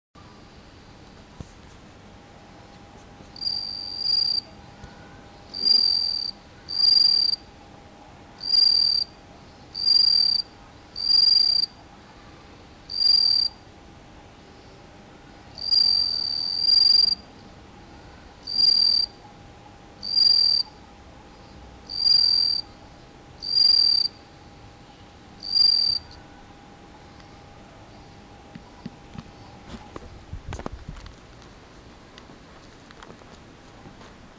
★秋を感じる音 ～豊里中のスズムシの鳴き声から～
先日、ＨＰでもお伝えしましたが、本校で育てているスズムシも、鳴き声が徐々に
豊里中の鈴虫の鳴き声.mp3